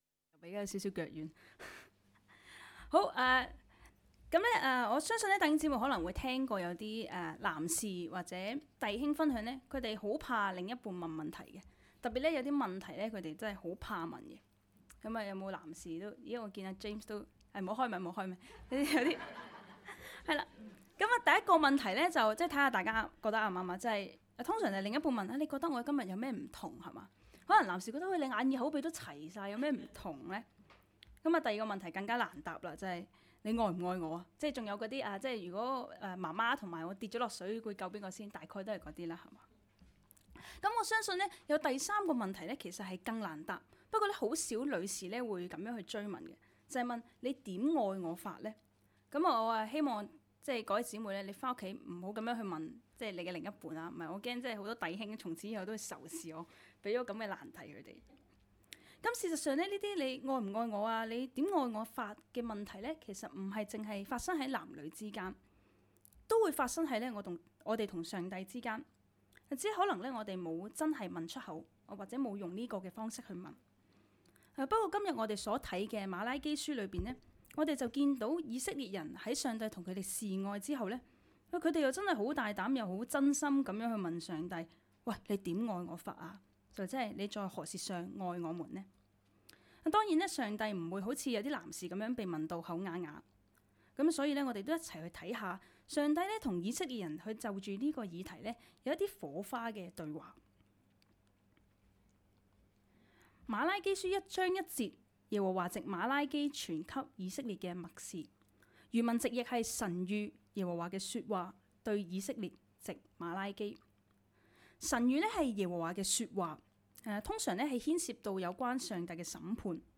講道 ：「你」在何事上愛了我?